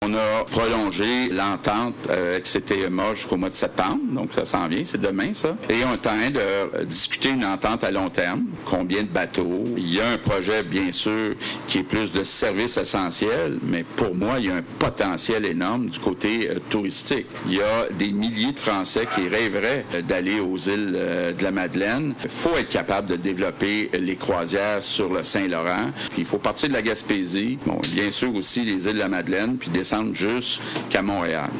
Point de presse du premier ministre François Legault accompagné de la ministre Marie-Ève Proulx à Carleton-sur-Mer